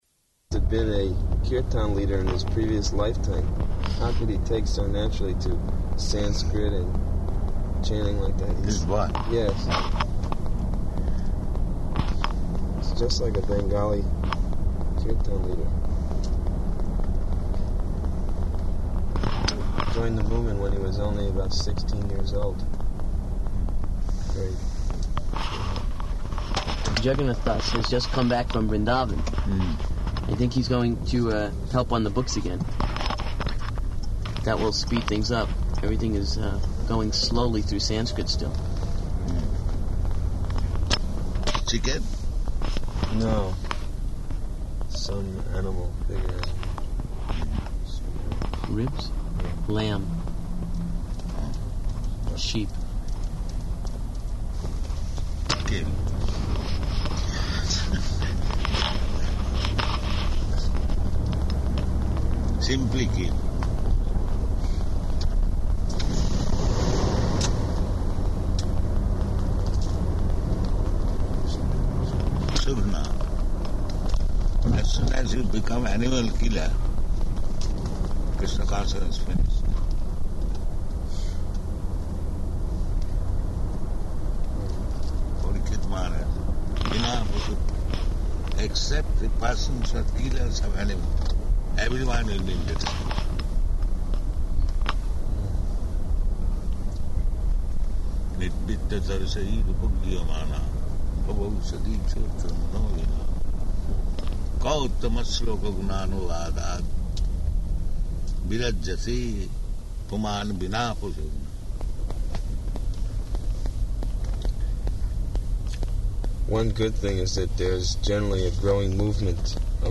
[in car]